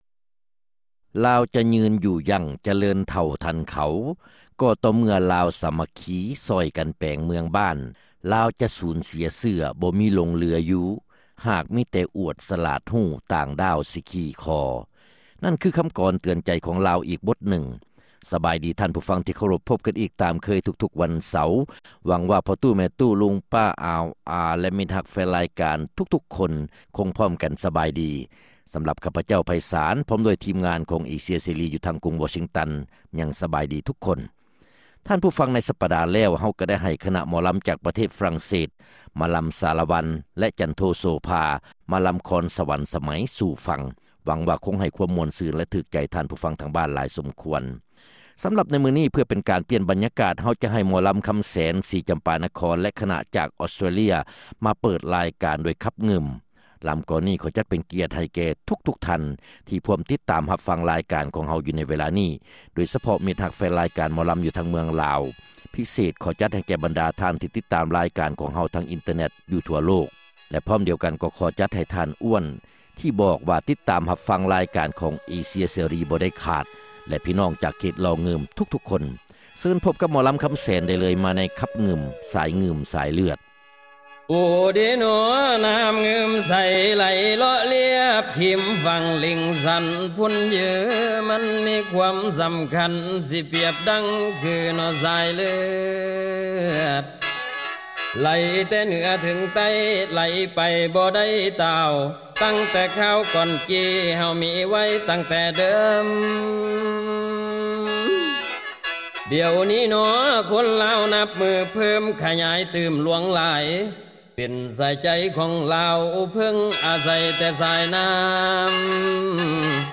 ຣາຍການໜໍລຳ ປະຈຳສັປະດາ ວັນທີ 24 ເດືອນ ກຸມພາ ປີ 2006